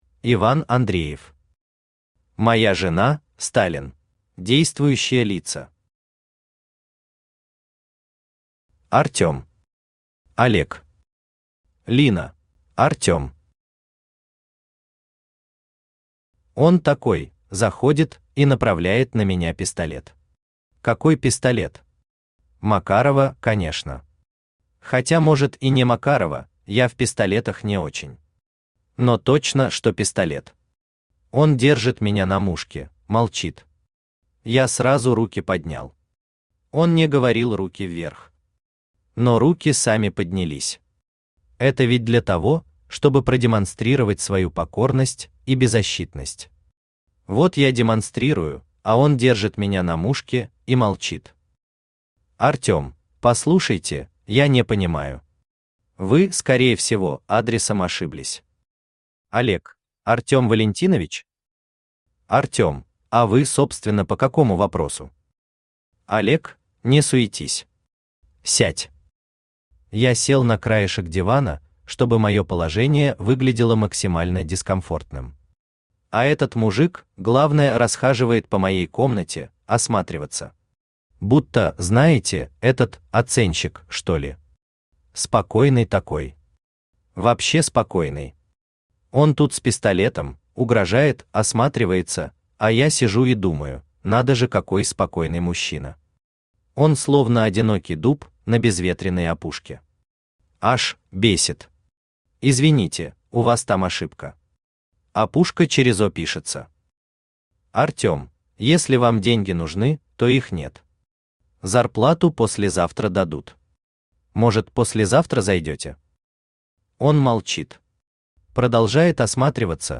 Читает аудиокнигу Авточтец ЛитРес.